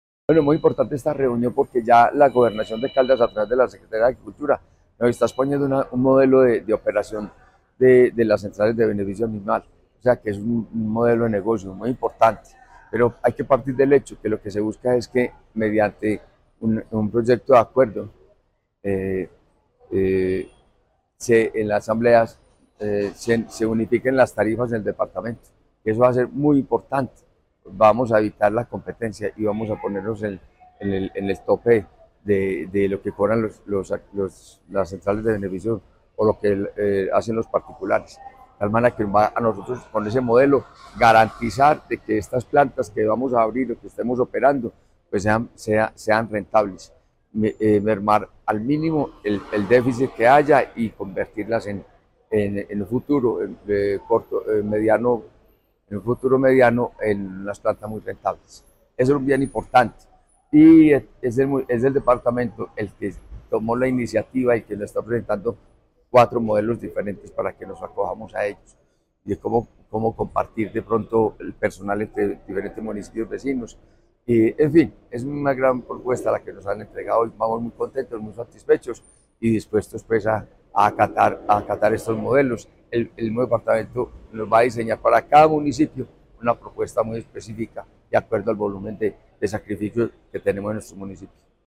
Fabio Gómez Mejía, alcalde de Aguadas.